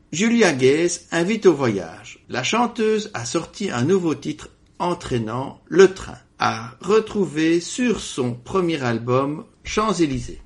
Nouveau talent de la chanson française